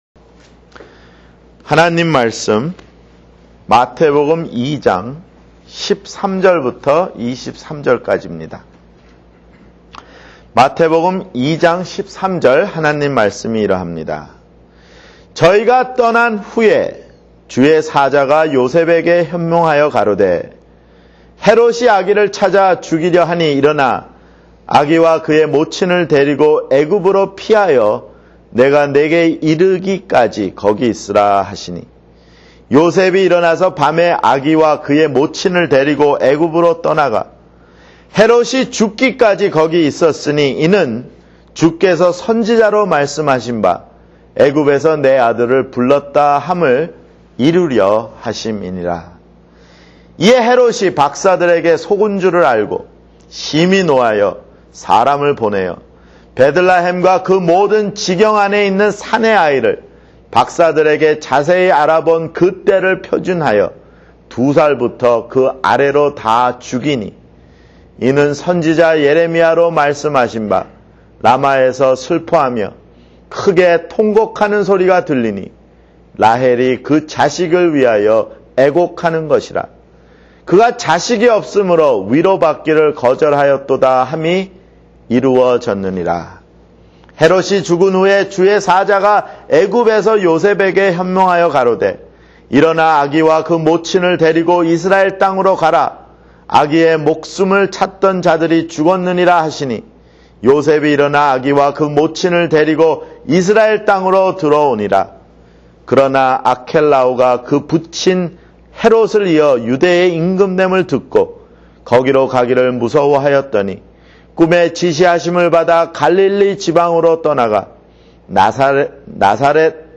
[주일설교] 마태복음 (6)